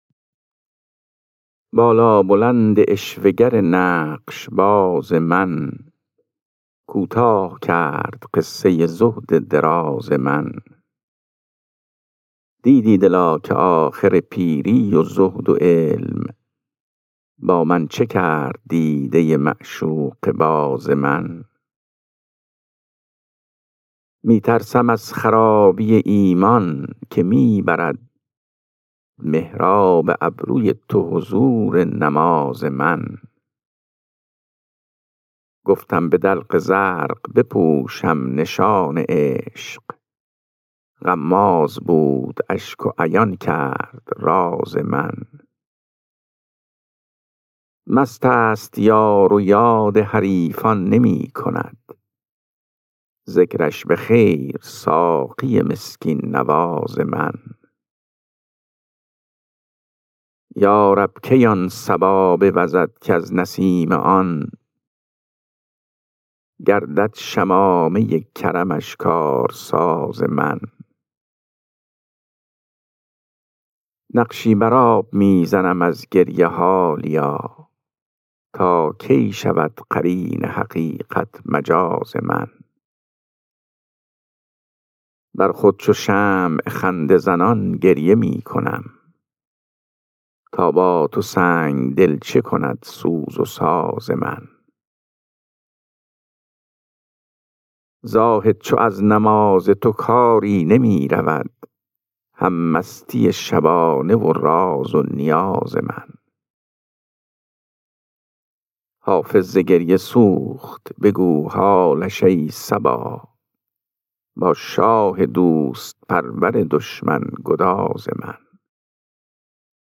خوانش غزل شماره 400 دیوان حافظ